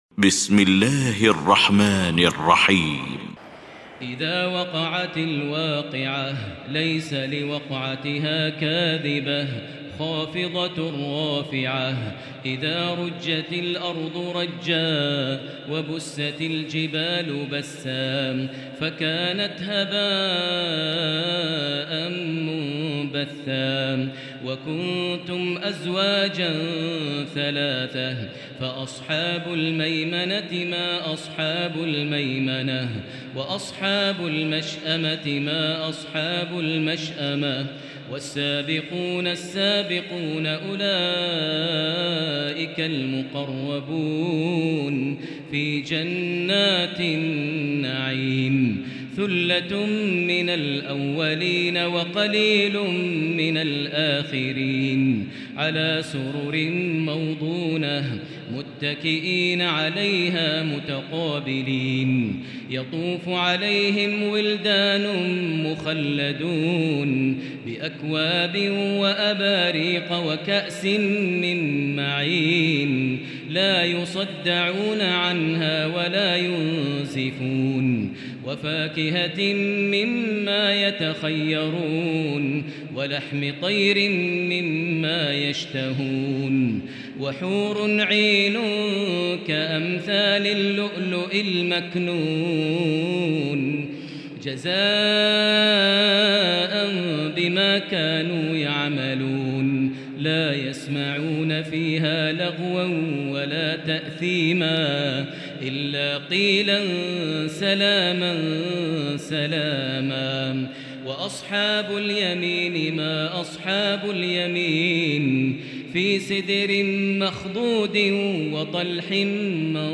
المكان: المسجد الحرام الشيخ: فضيلة الشيخ ماهر المعيقلي فضيلة الشيخ ماهر المعيقلي الواقعة